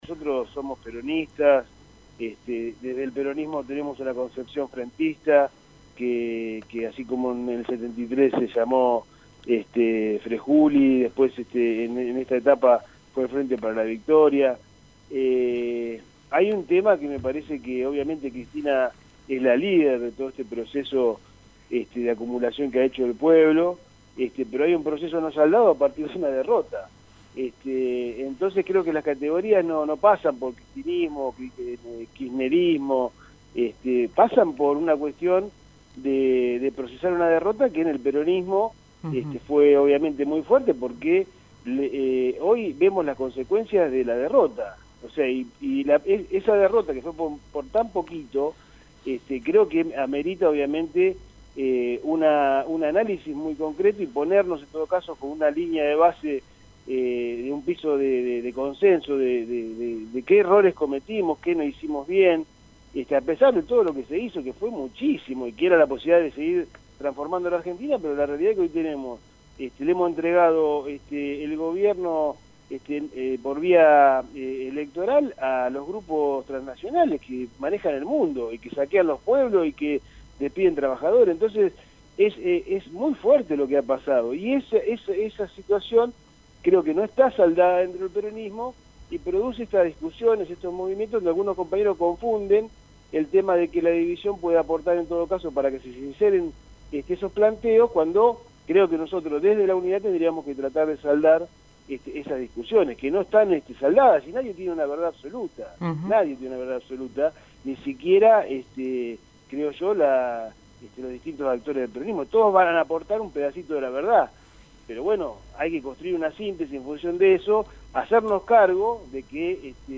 «Nosotros somos peronistas, y desde el peronismo tenemos una concepción frentista», explicó el diputado bonaerense y respecto a la figura de la ex-presidenta opinó: